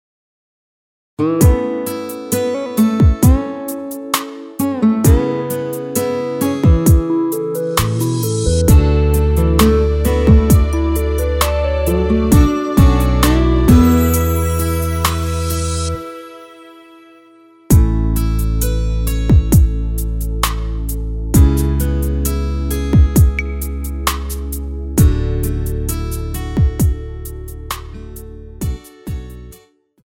원키에서(-5)내린 MR입니다.
앞부분30초, 뒷부분30초씩 편집해서 올려 드리고 있습니다.